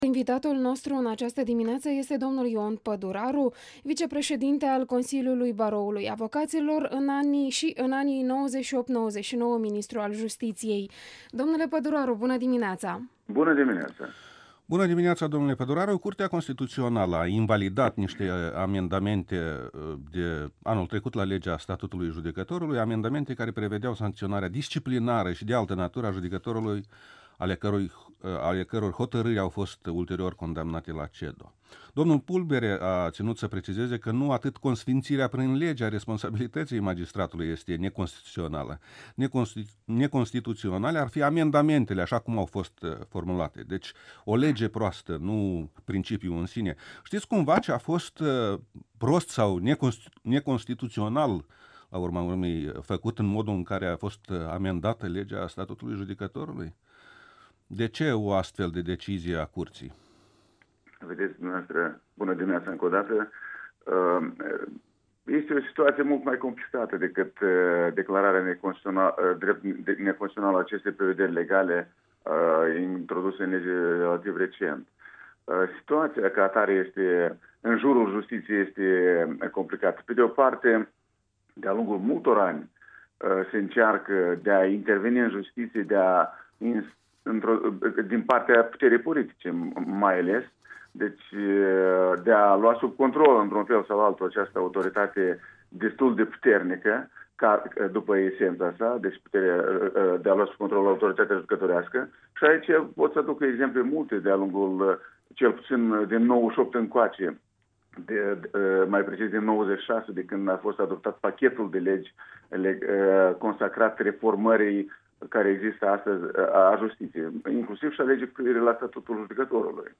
Interviul matinal EL: despre justiție cu un fost ministru Ion Păduraru